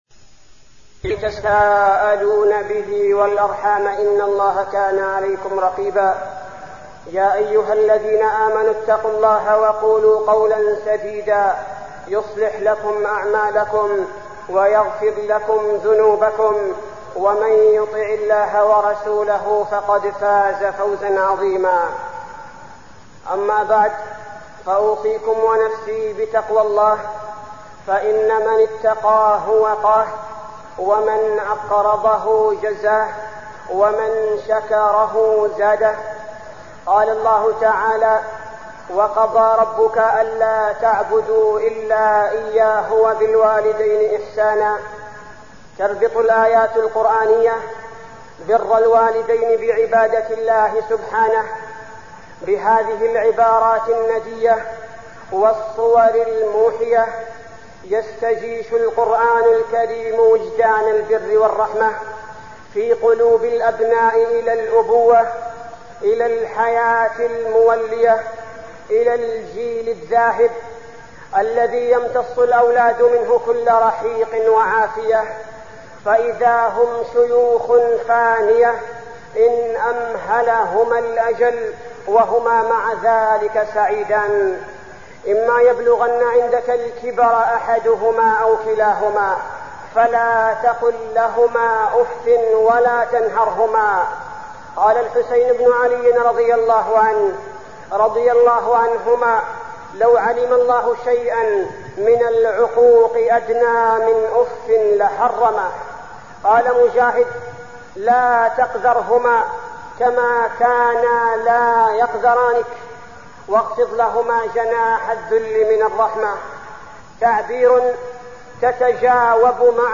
تاريخ النشر ٦ جمادى الآخرة ١٤١٧ هـ المكان: المسجد النبوي الشيخ: فضيلة الشيخ عبدالباري الثبيتي فضيلة الشيخ عبدالباري الثبيتي برالوالدين